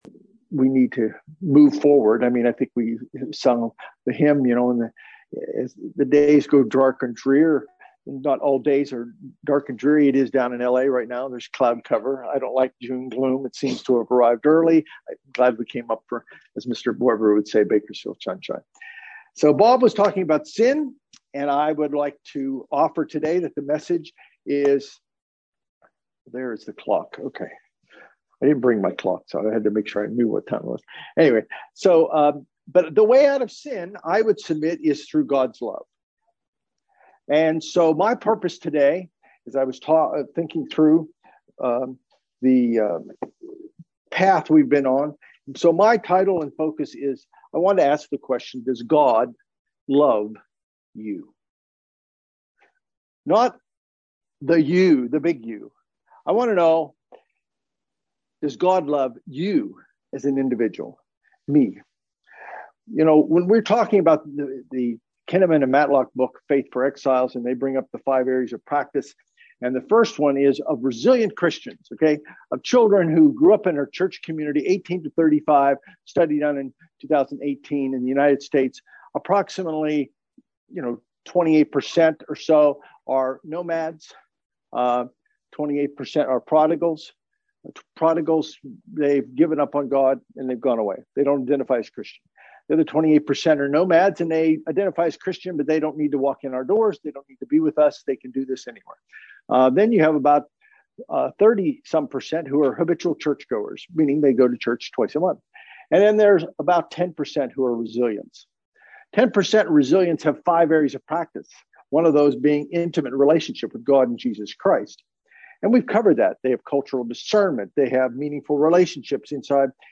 Given in Bakersfield, CA